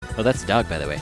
And this is the shutdown sound: